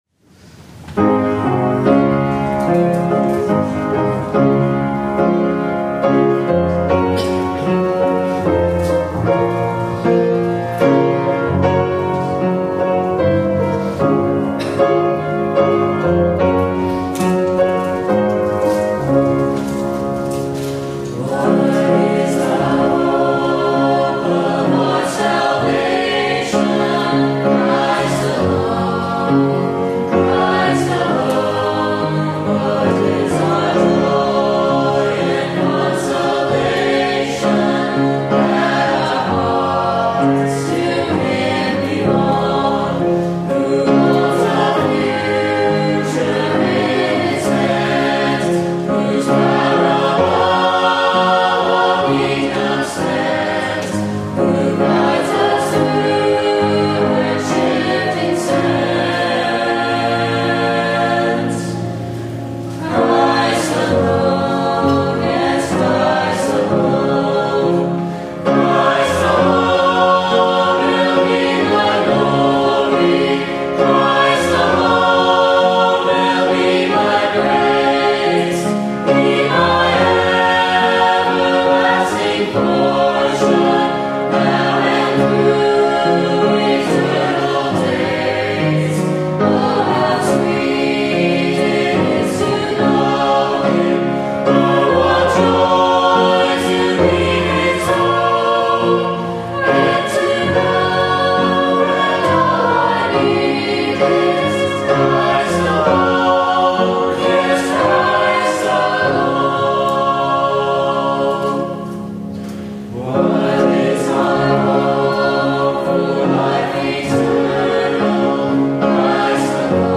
From Series: "Revival Sermons"
Sermons preached during special evening services - usually evangelistic.